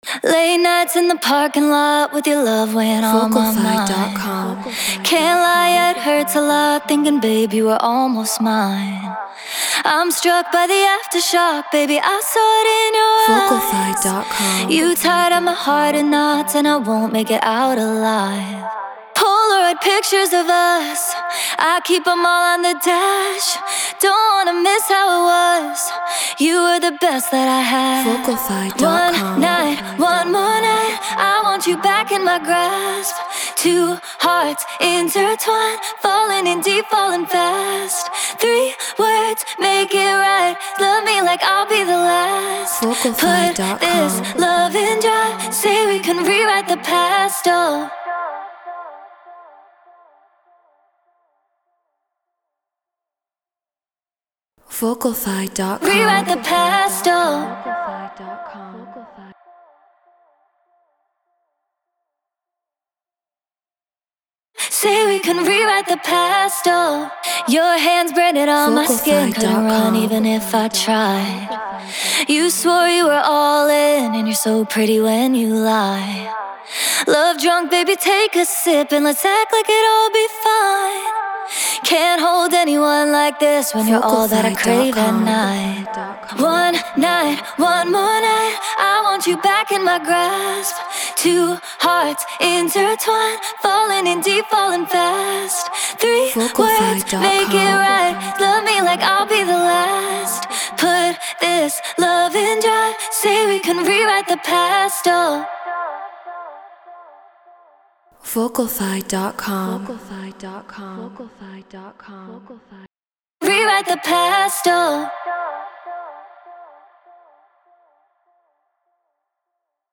Future Pop 100 BPM Fmaj
Human-Made